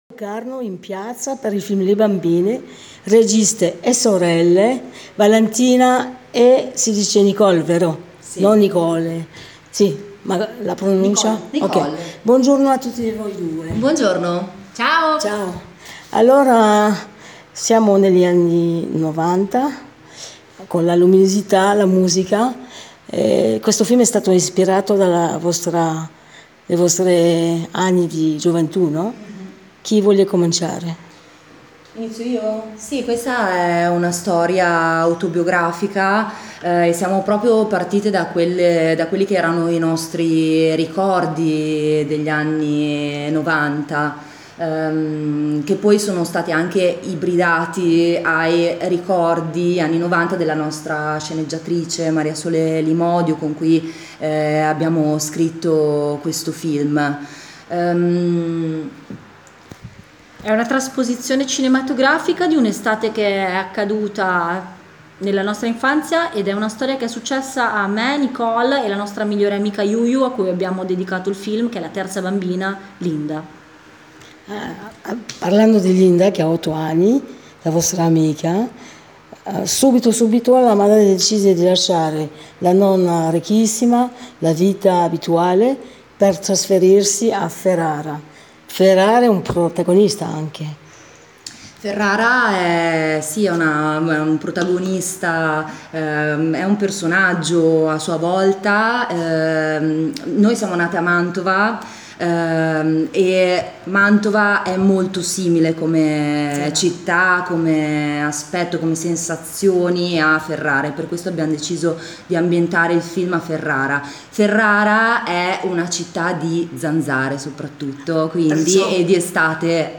Intervista - j:mag